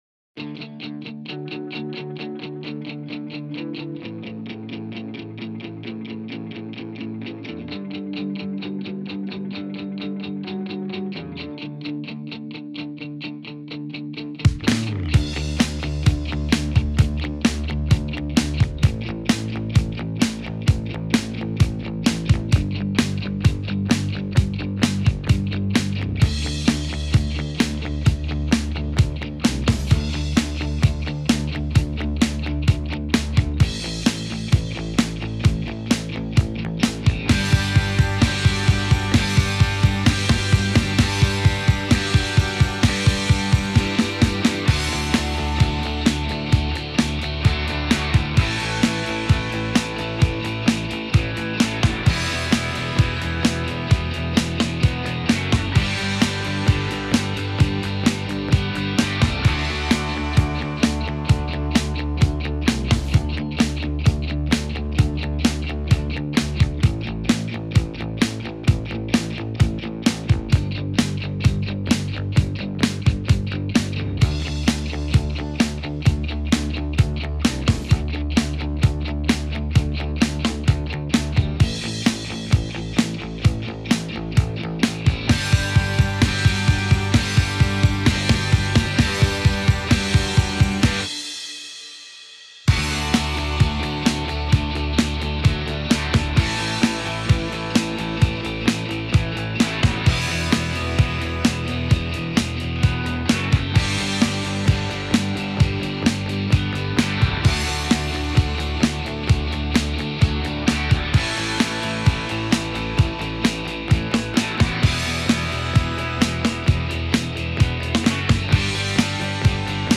It's just drums, bass, some guitars recorded thru AmpSim and no vocals.
Tempo 130Bpm